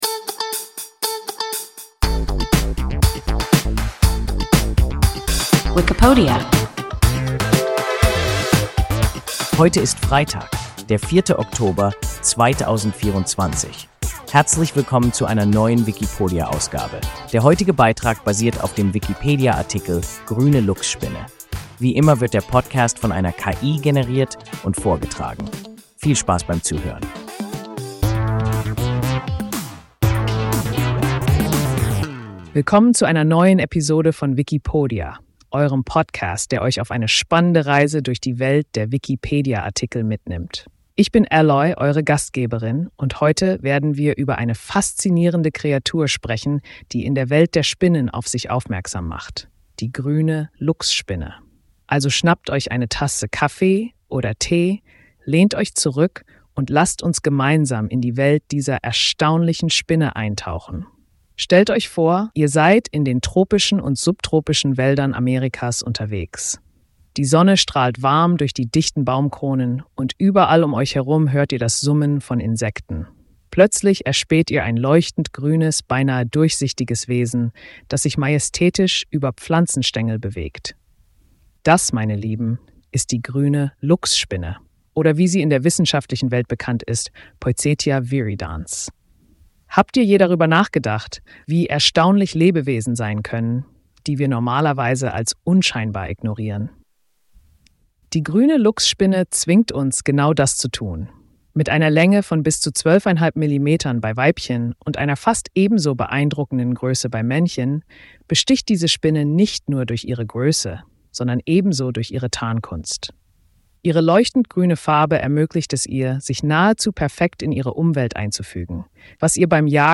Grüne Luchsspinne – WIKIPODIA – ein KI Podcast